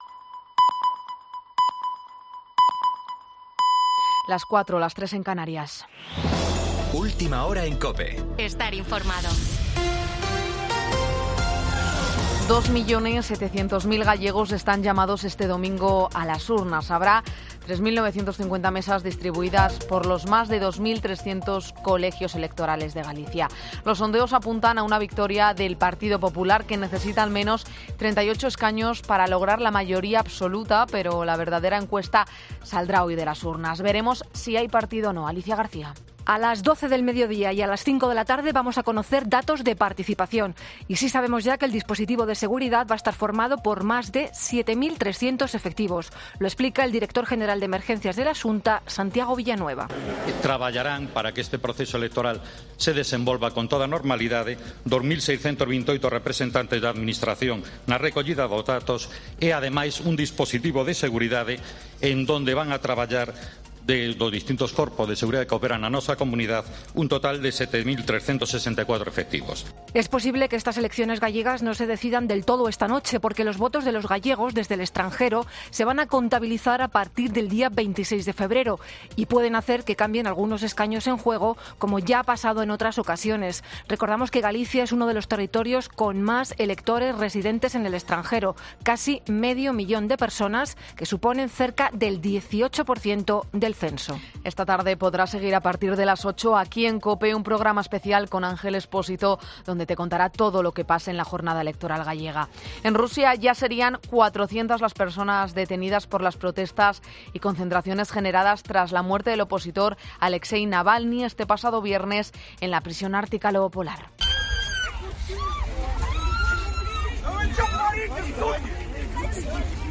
AUDIO: Boletín 04.00 horas del 18 de febrero de 2024